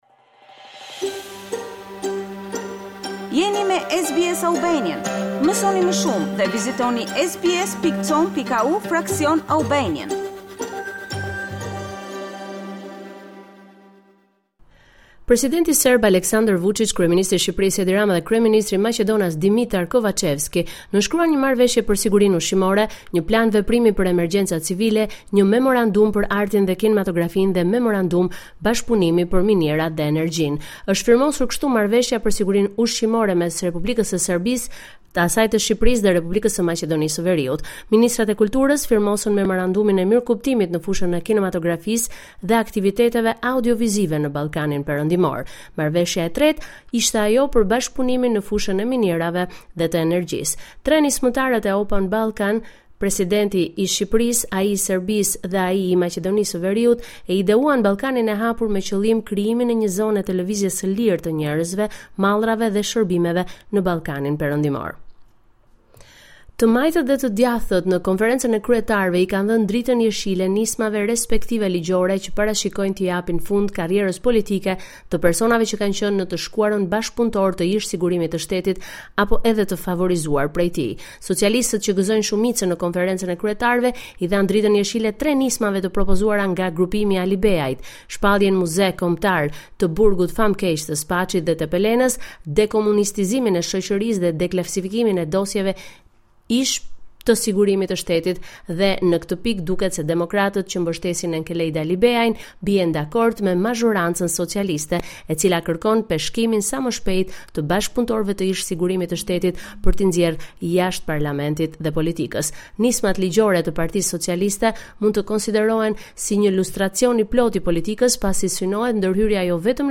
This is a report summarizing the latest developments in news and current affairs in Albania.